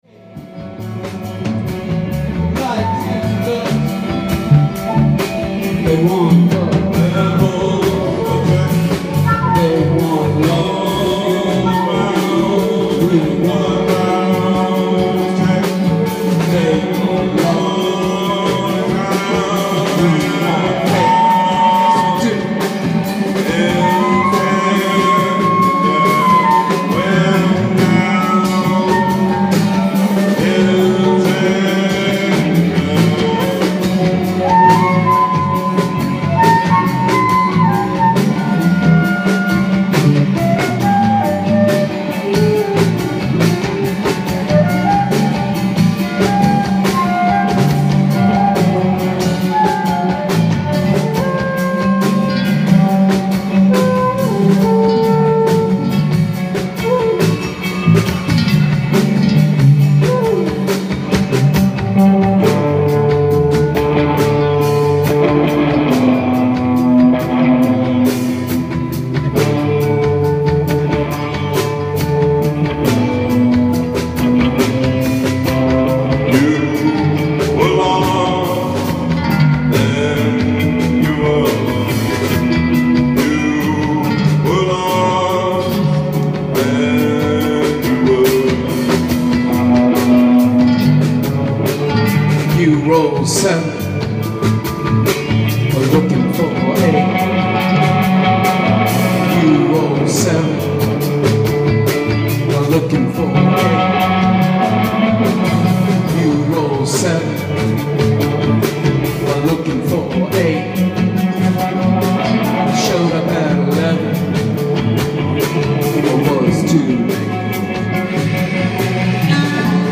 Magic Number drums
guitar
sitar/voice
flute
bass/keys/tympanis
ALL MUSIC IS IMPROVISED ON SITE